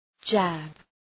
{dʒæb}